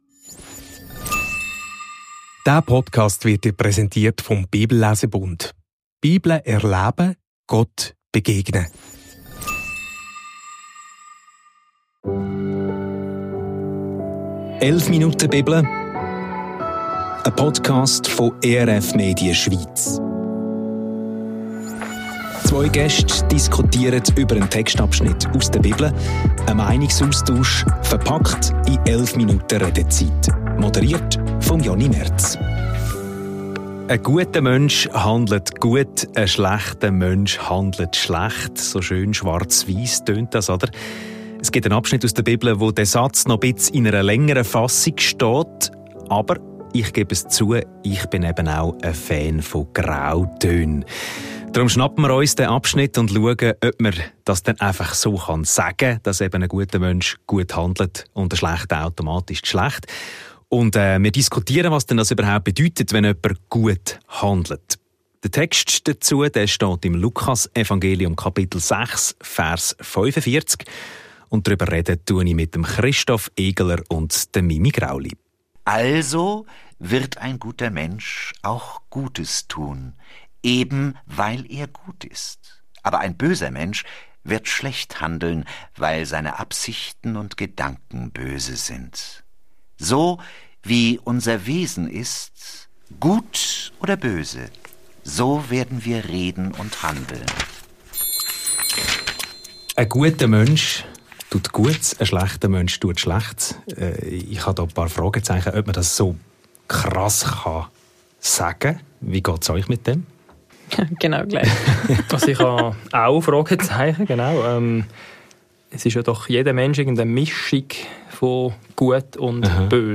Sind Christen automatisch bessere Menschen? – Lukas 6,45 ~ 11 Minuten Bibel – ein Meinungsaustausch Podcast